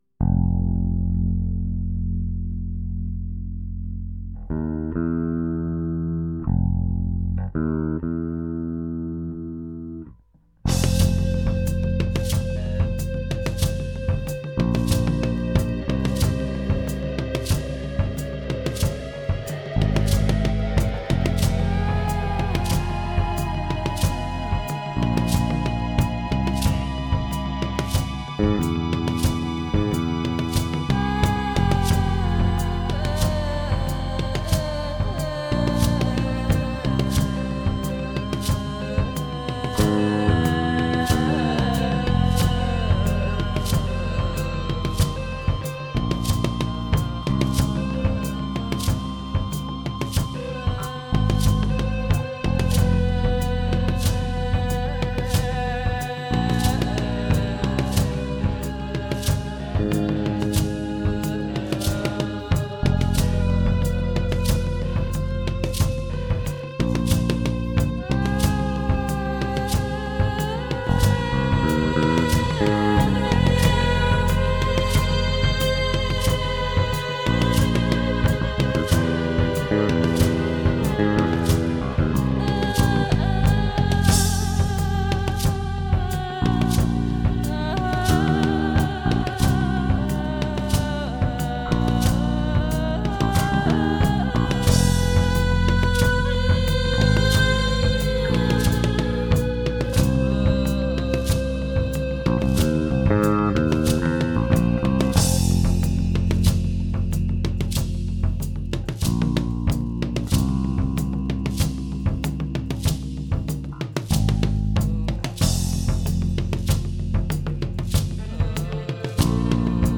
drums, Casio synthesizer, percussion, vibraphone, Synare II
bass, percussion, keyboards